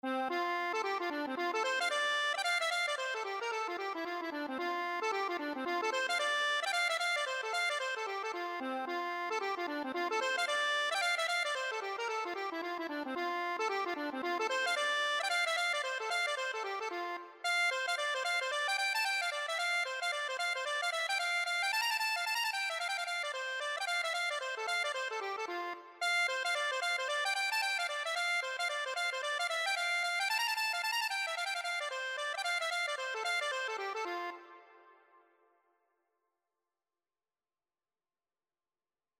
Free Sheet music for Accordion
Traditional Music of unknown author.
F major (Sounding Pitch) (View more F major Music for Accordion )
2/4 (View more 2/4 Music)
C5-Bb6
Accordion  (View more Easy Accordion Music)
Traditional (View more Traditional Accordion Music)